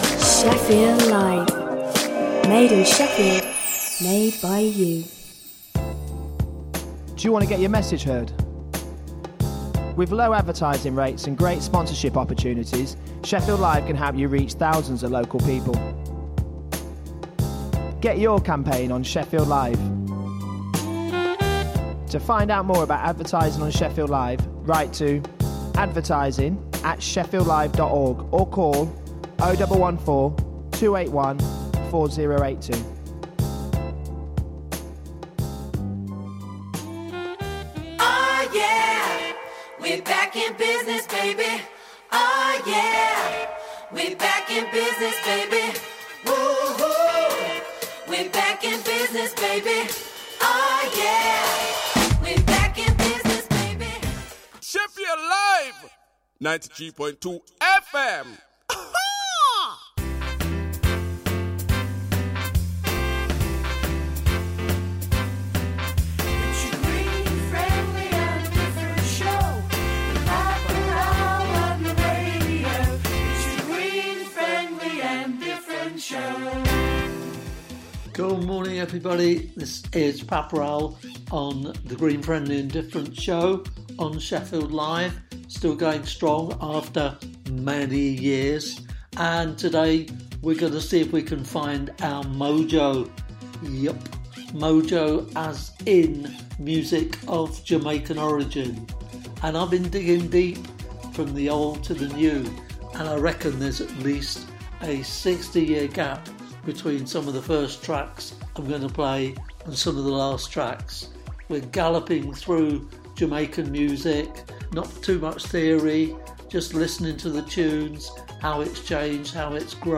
Information about radical environmental projects, innovative regeneration activities, views on the city’s development and off-the-wall cultural projects with a wide range of music from across the world.